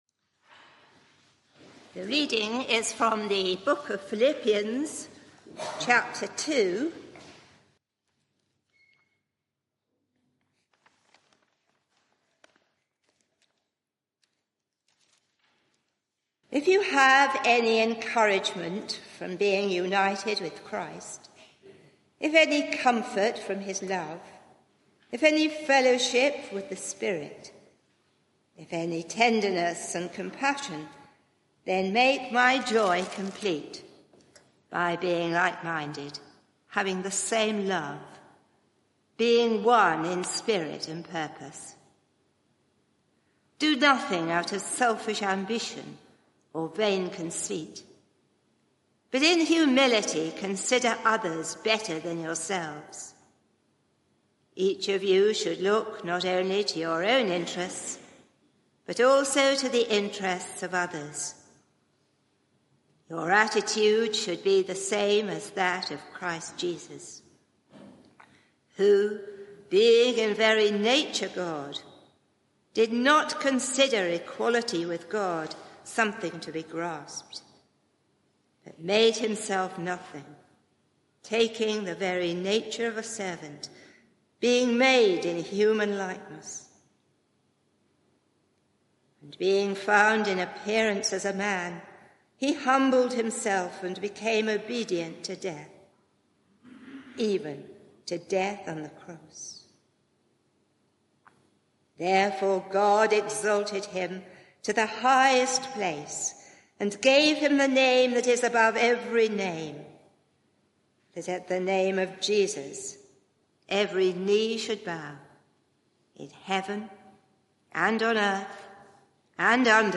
Media for 11am Service on Sun 07th Apr 2024 11:00 Speaker
Sermon (audio) Search the media library There are recordings here going back several years.